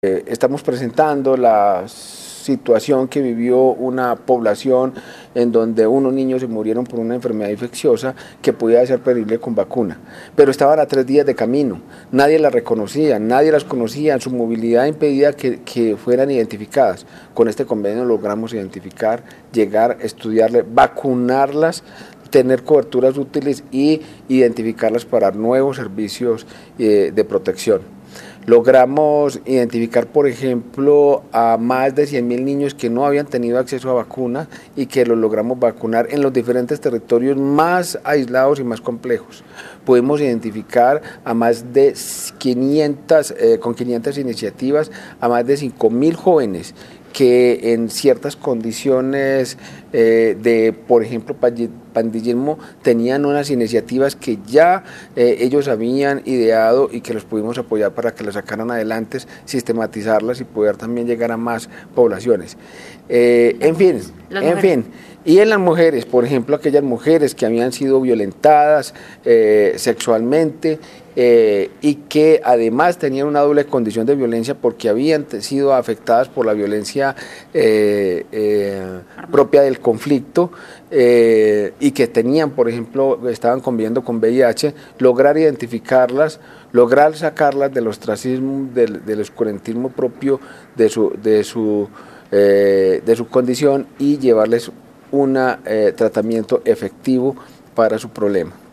Audio: el Director de Promoción y Prevención, Elkin De Jesús Osorio habla sobre los Resultados Convenio MinSalud - OIM